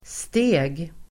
Uttal: [ste:g]